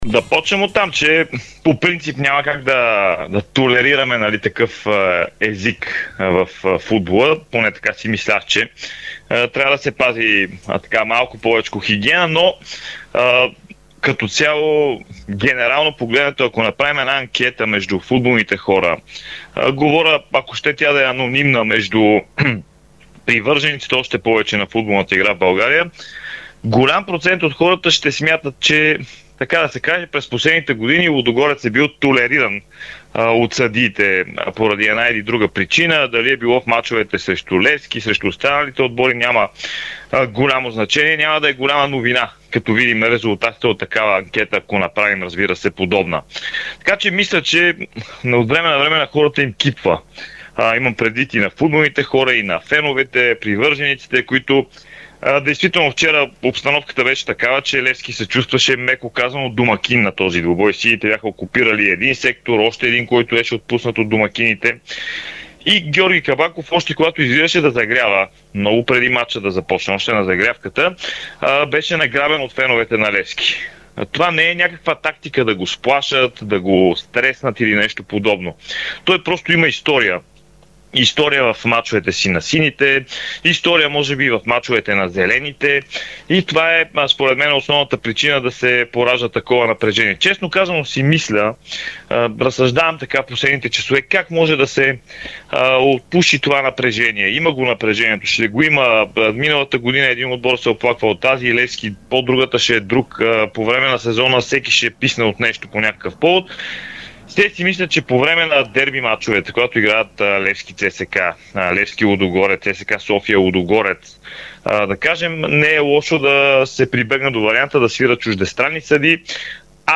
Коментар
направи коментар в неделното издание на Спортното шоу в ефира на Дарик радио по темата дали главният рефер Георги Кабаков повлия на крайния изход на дербито Лудогорец - Левски, загубено с 0:1 от „сините“.